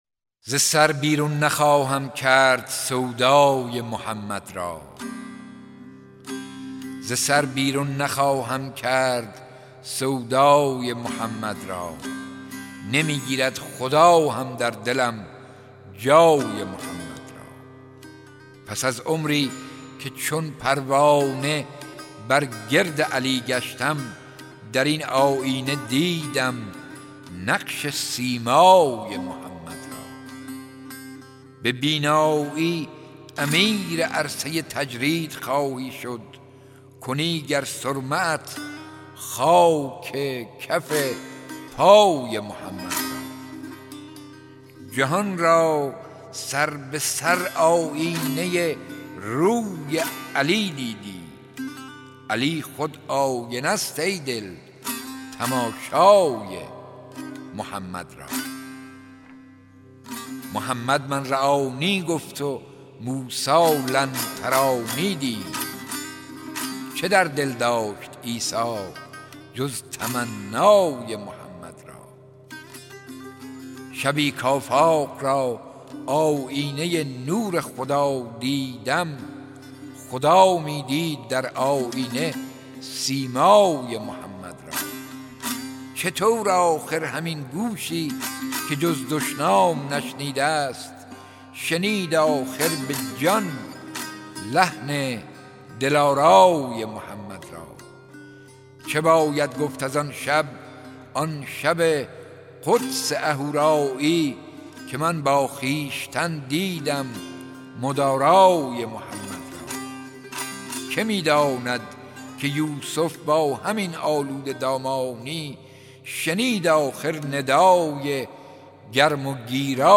شعر خوانی میرشکاک درباره پیامبر + صوت
یوسفعلی میرشکاک شاعر پرآوازه کشورمان شعری در وصف پیامبر بزرگ و مهربانمان دارد با نام داغ سودای محمد که این شعر را با صدای خودش دکلمه کرده است.